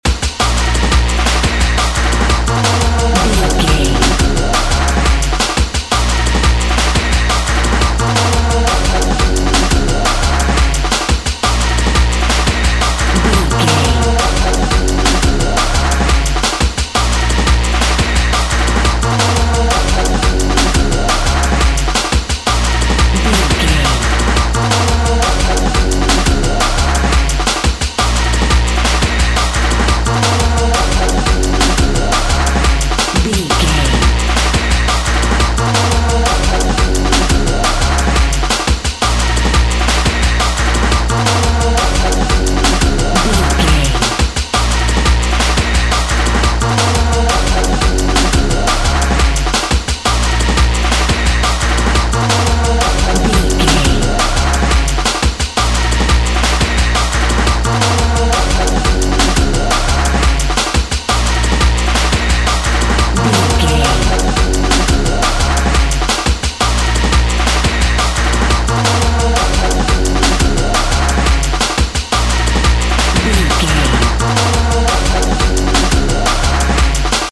Electric Drum and Bass.
Epic / Action
Fast paced
In-crescendo
Aeolian/Minor
B♭
aggressive
dark
energetic
driving
futuristic
drum machine
synthesiser
break beat
sub bass
synth lead
synth bass